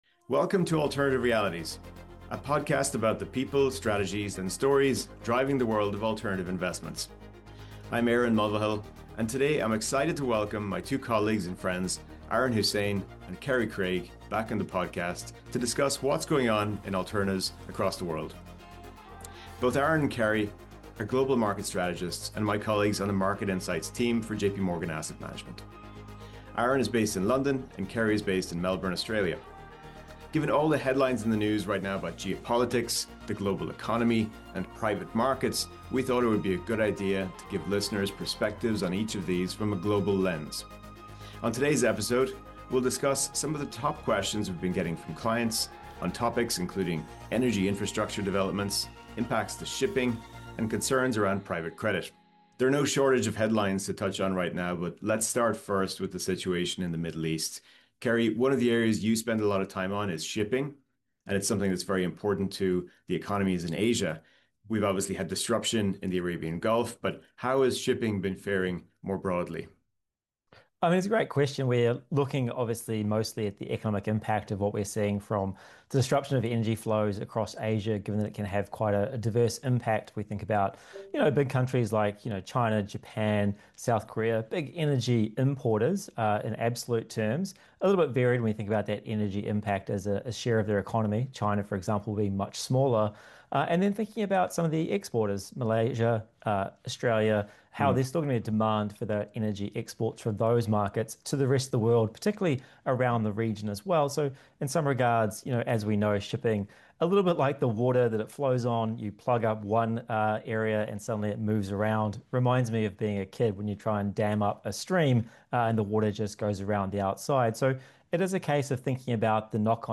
for a candid conversation about the questions foremost in clients’ minds around the world. Together, they unpack the latest developments in energy and infrastructure, explore how global shipping routes are affecting supply chains, and address the growing concerns and opportunities in private credit.